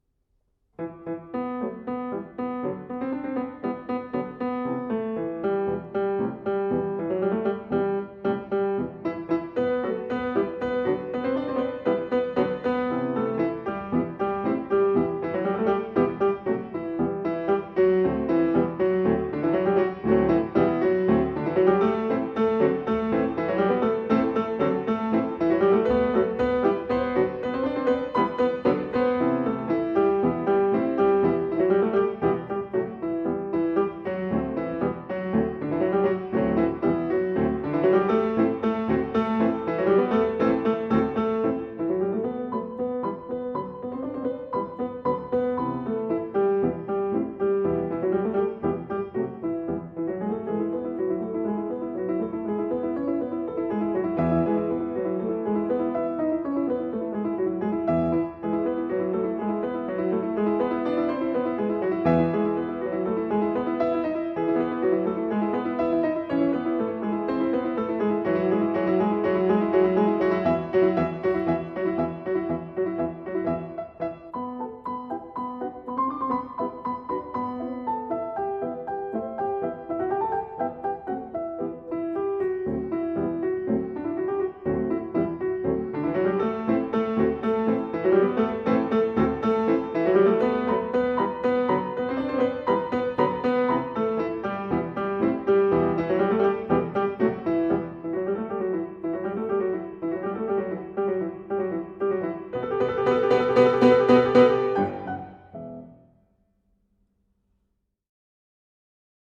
For Piano. Humoreske (Allegro)